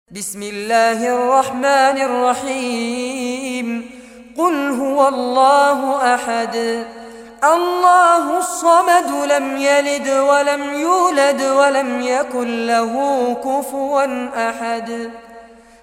Surah Al-Ikhlas Recitation by Fares Abbad
Surah Al-Ikhlas, listen or play online mp3 tilawat / recitation in Arabic in the beautiful voice of Sheikh Fares Abbad.
112-surah-ikhlas.mp3